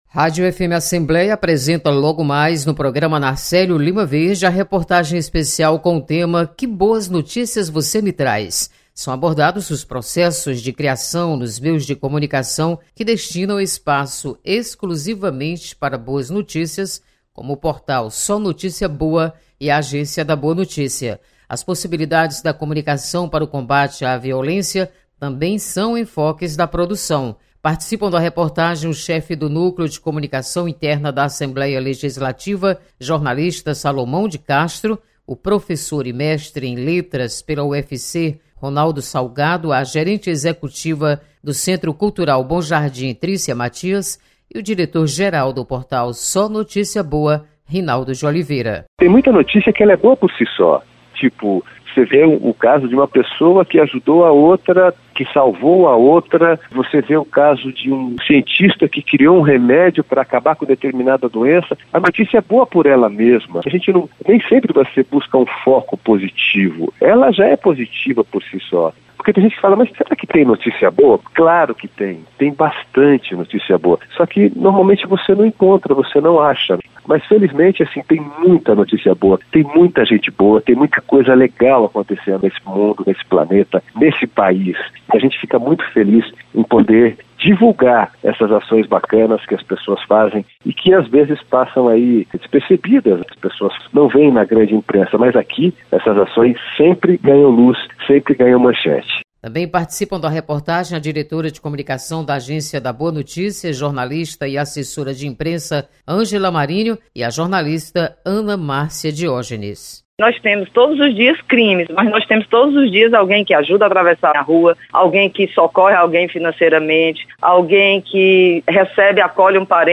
Escritório Frei Tito de Alencar alerta para falta de registro de recém nascidos Repórter